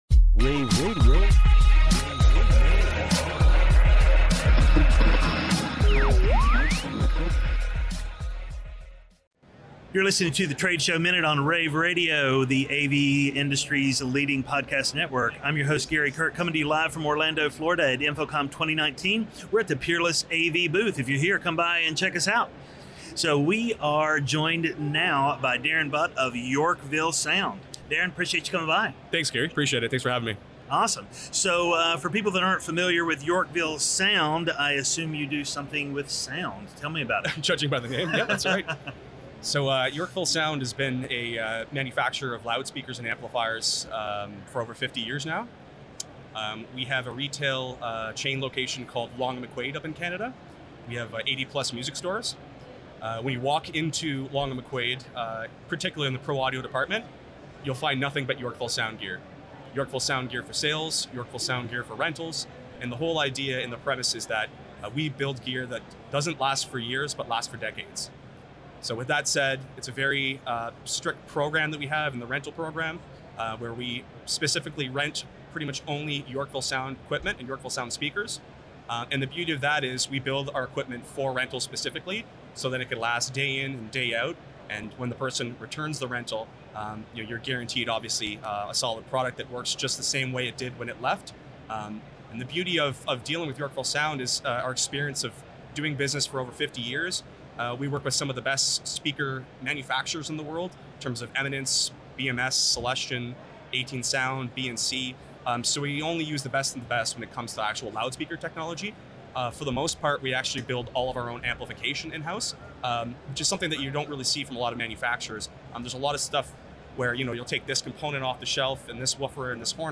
June 13, 2019 - InfoComm, InfoComm Radio, Radio, The Trade Show Minute,